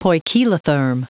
Prononciation du mot poikilotherm en anglais (fichier audio)
Prononciation du mot : poikilotherm